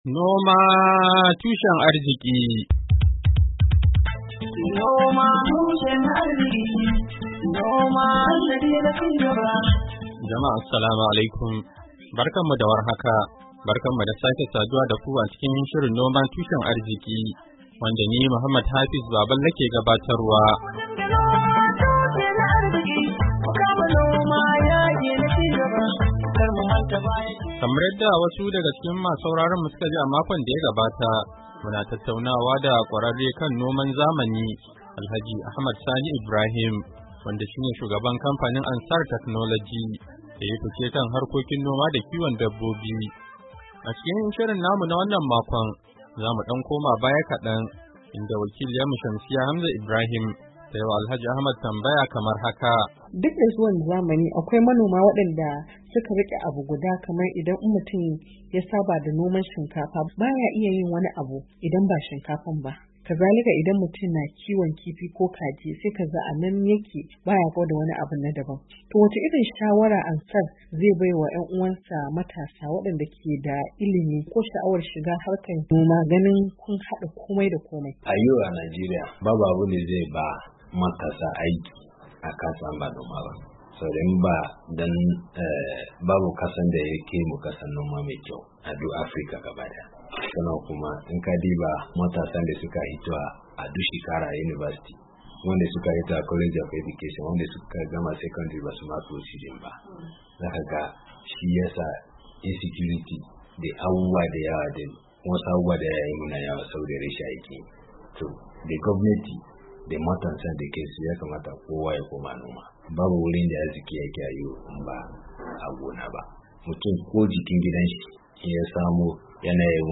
NOMA TUSHEN ARZIKI: Hira Da Kwararre Kan Noma Da Kiwon Dabbobi Na Zamani a Najeriya - Kashi Na Uku - Janairu 17, 2023